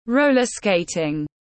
Môn trượt pa-tin tiếng anh gọi là roller skating, phiên âm tiếng anh đọc là /ˈroʊlər ˈskeɪtɪŋ/
Roller skating /ˈroʊlər ˈskeɪtɪŋ/